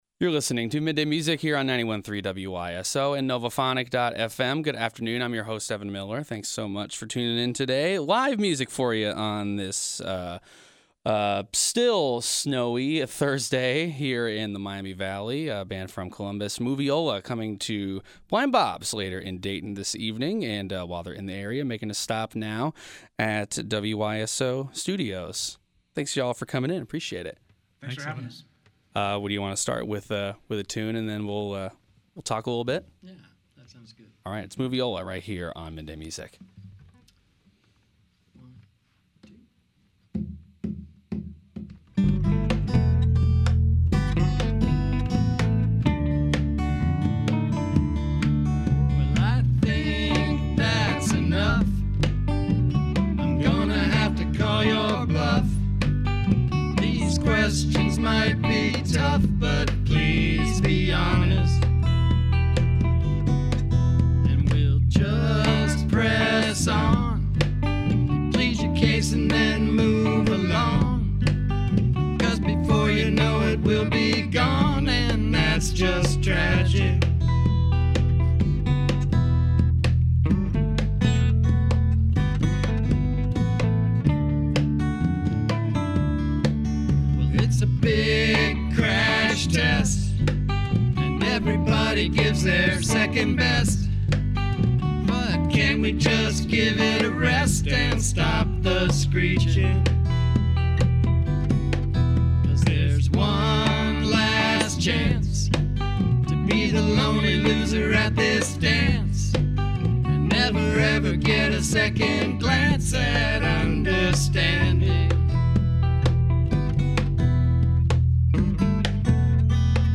Studio Session